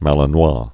(mălən-wä)